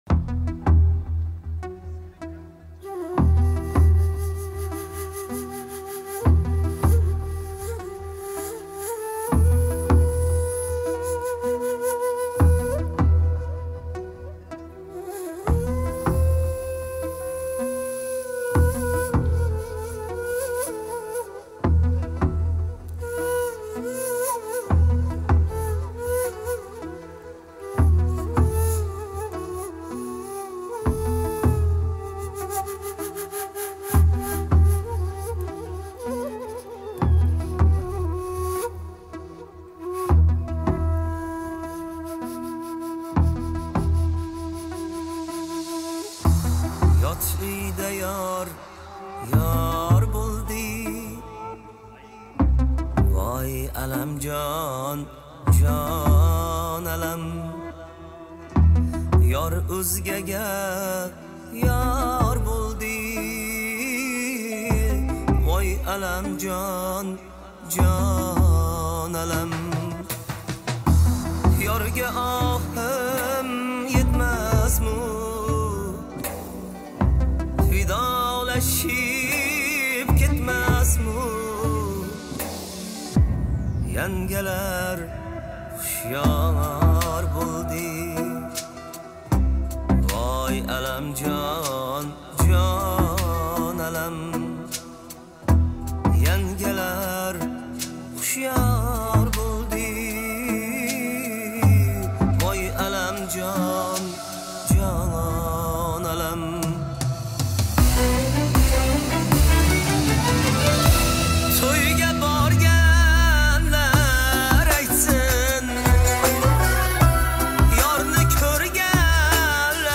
consert version